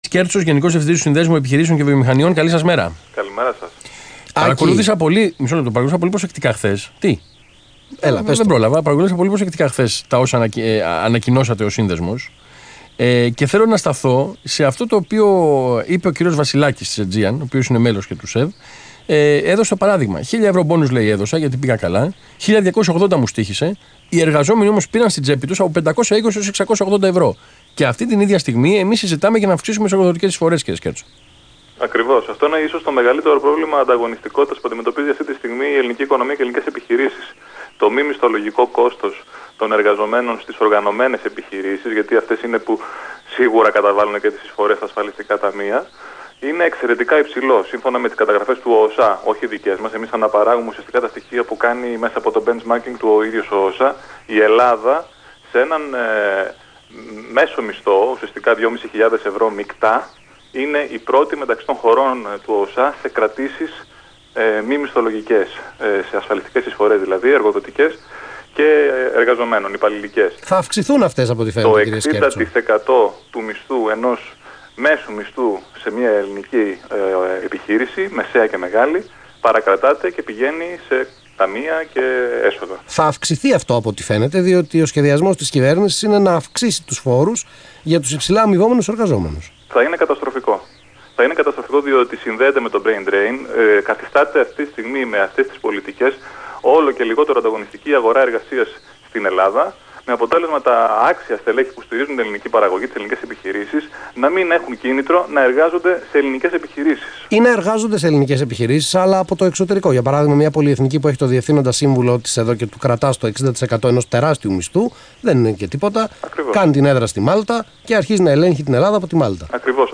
Συνέντευξη του κ. Άκη Σκέρτσου, Γενικού Διευθυντή του ΣΕΒ στον Αθήνα 9.84, 6/11/15